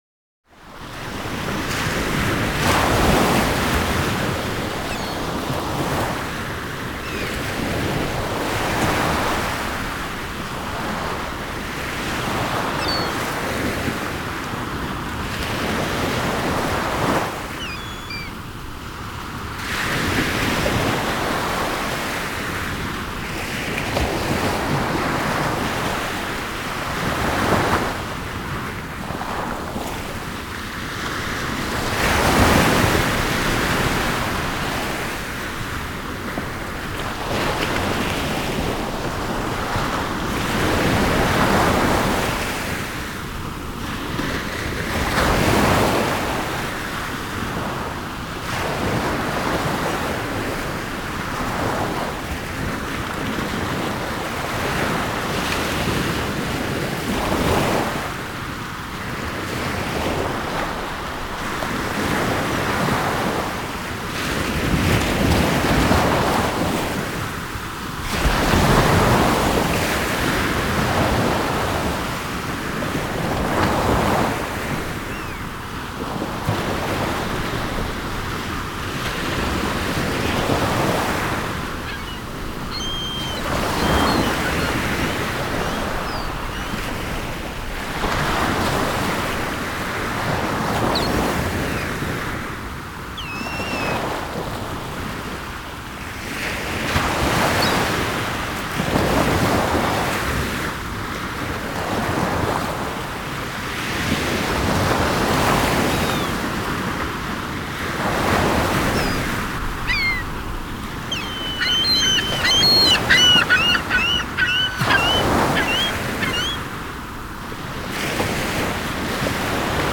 Звуки лета
Шепот летнего заката у моря, солнце опускается